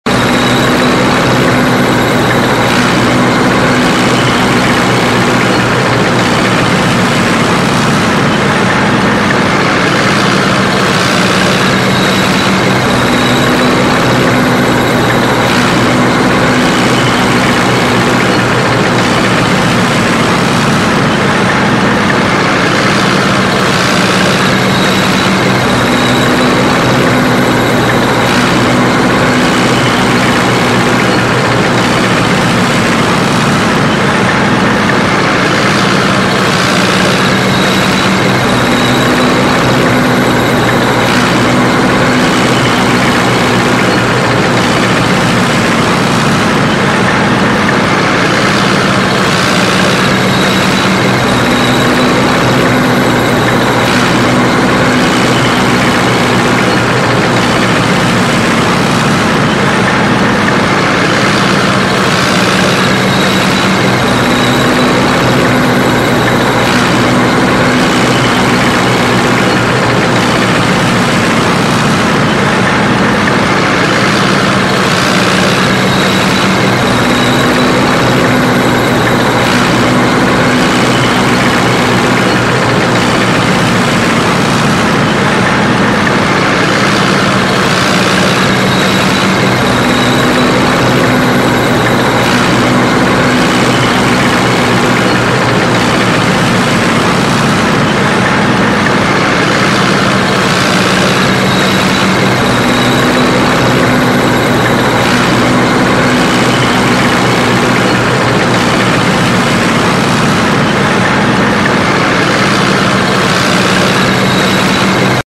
دانلود آهنگ تانک 2 از افکت صوتی حمل و نقل
دانلود صدای تانک 2 از ساعد نیوز با لینک مستقیم و کیفیت بالا
جلوه های صوتی